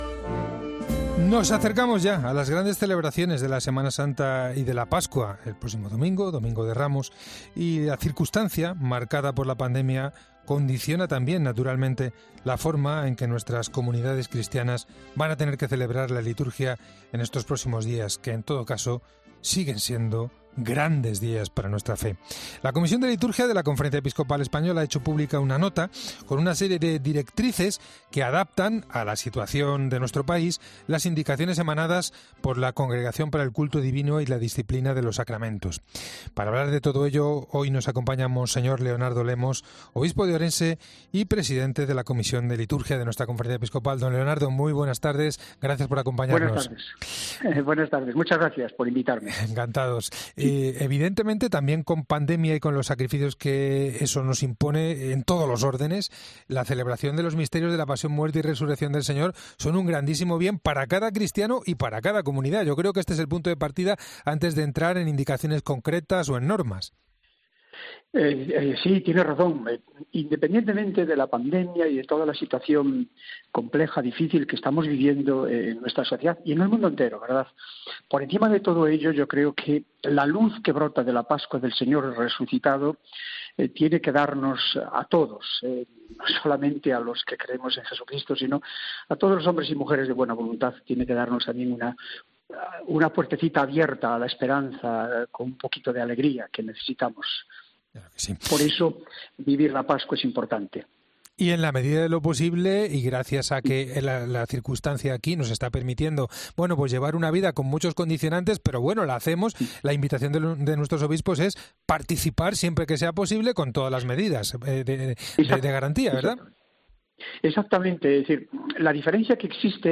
Mons. Leonardo Lemos es el presidente de la Comisión Episcopal para la Liturgia de la Conferencia Episcopa l desde marzo de 2020 y nos atiende en los micrófonos de El Espejo de la Cadena COPE A pesar de la situación que vivimos, sigue siendo Semana Santa y mons.